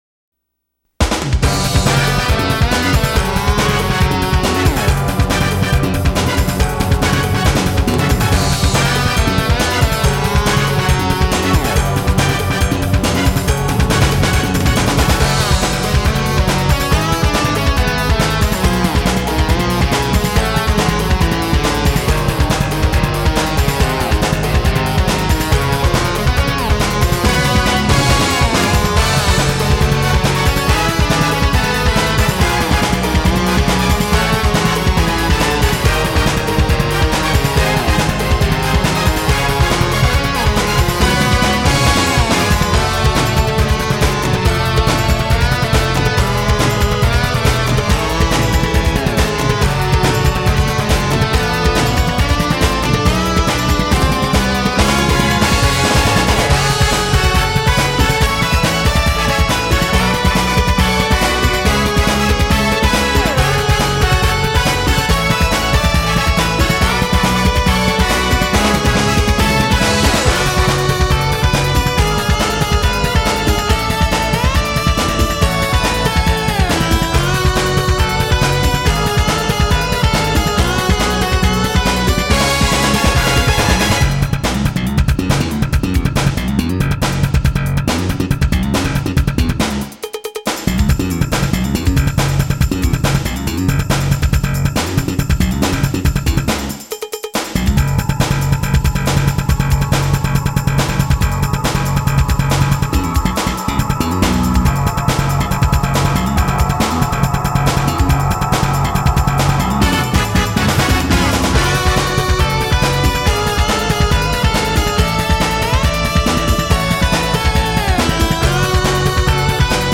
なお、掲載している音源には、EQとコンプレッサー等で若干のマスタリング処理を施してあります。
前曲でも使用されていた、Rolandの拡張カードSN-U110-07によるギターサウンドが、本曲でもリードギターとして全面的に用いられています。